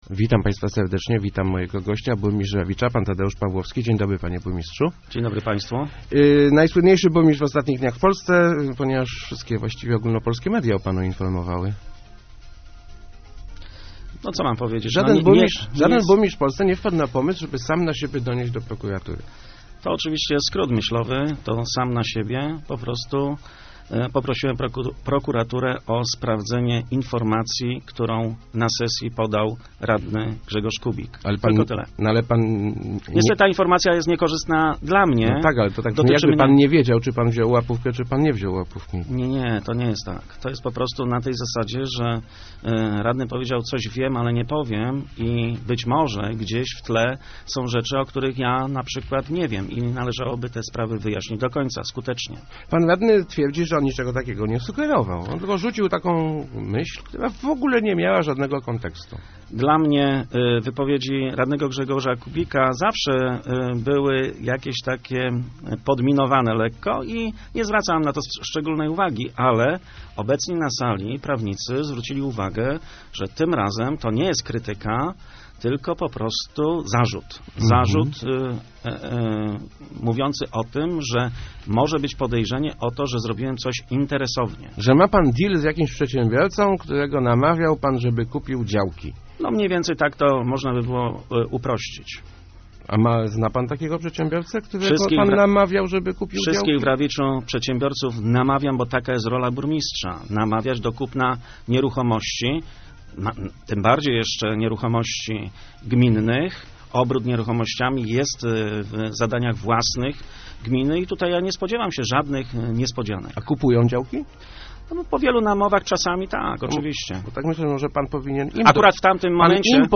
Moi prawnicy zwrócili uwagę, że zarzut brzmi poważnie - mówił w Rozmowach Elki burmistrz Rawicza Tadeusz Pawłowski, tłumacząc dlaczego złożył zawiadomienie do prokuratury w swojej sprawie. Przyznał, że był już przesłuchiwany, jednak jak rozmowa wyglądała nie chciał mówić.